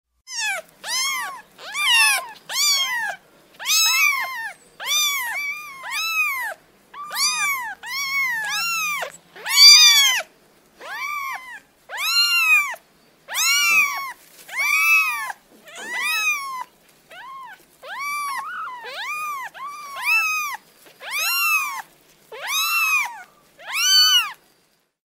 • Качество: высокое
Мяукающие котята звучат